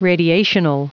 Prononciation du mot : radiational